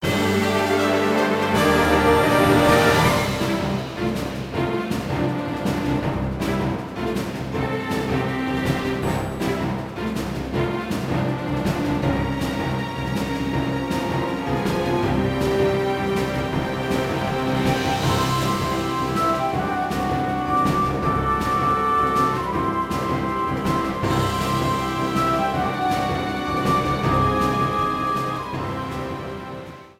Trimmed and fade out
This is a sample from a copyrighted musical recording.